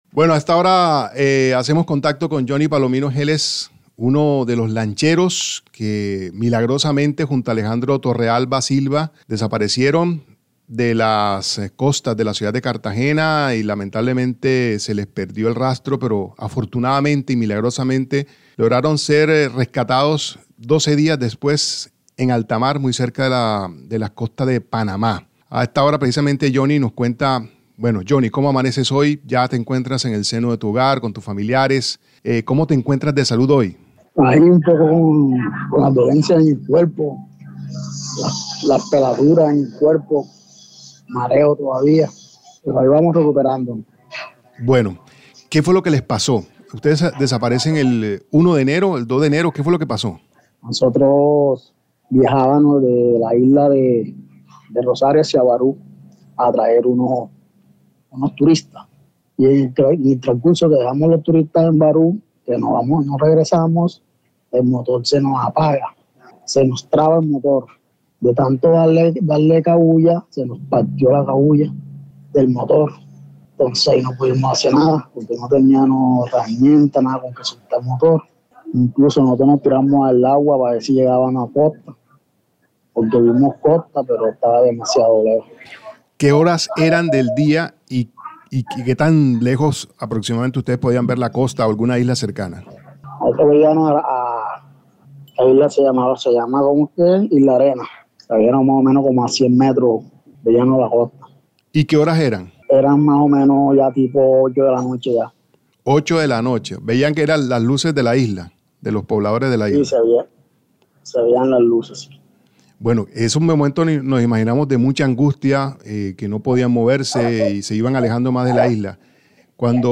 Caracol Radio habló con los lancheros que sobrevivieron 12 días en el mar, a la deriva, tomando su orina y comiendo algas.